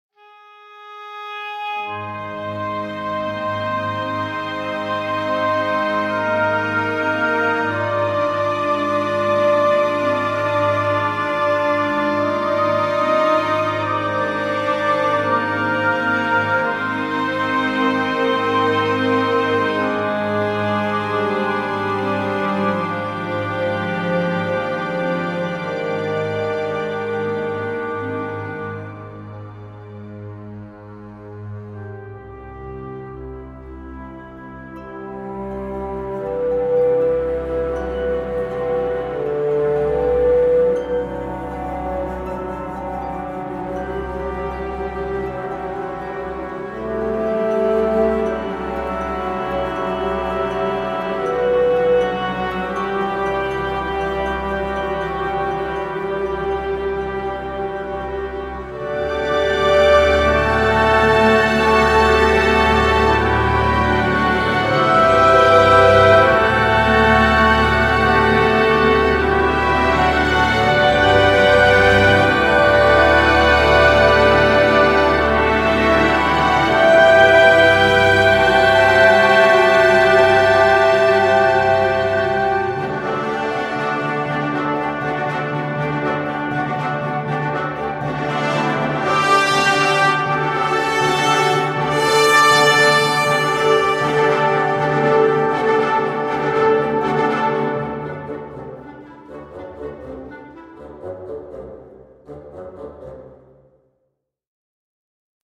富有表现力的木管乐器
在奥克兰蒙特克莱尔长老会教堂独特的声学环境中录制了顶级音乐家演奏的36件木管乐器。